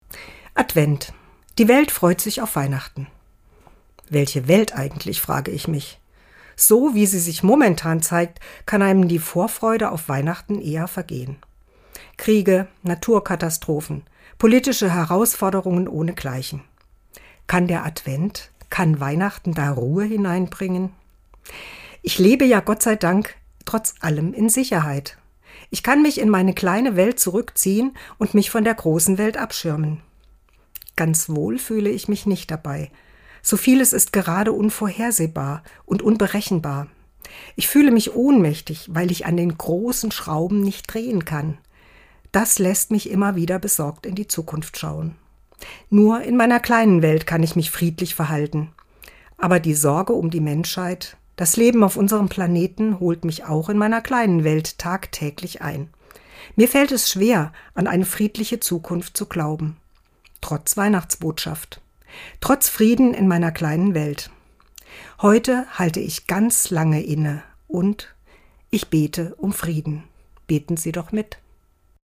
Dezember 2024, Autorin und Sprecherin ist